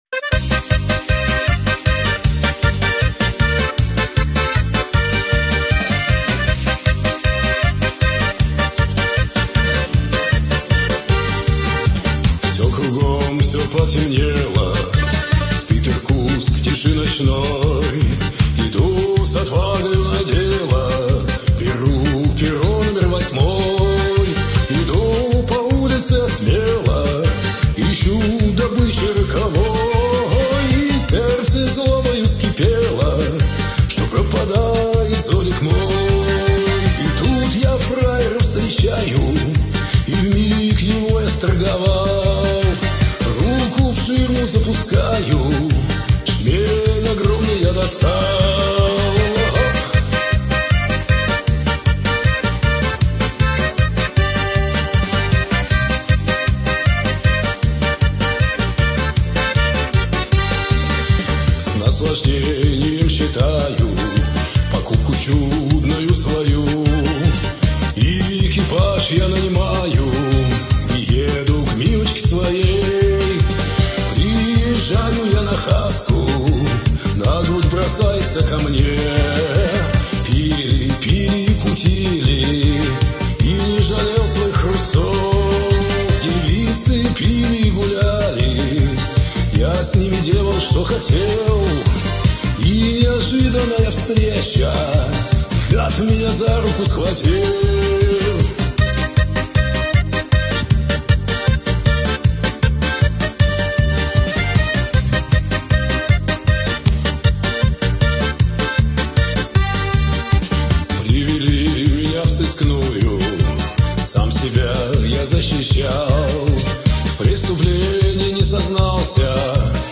• Жанр: Шансон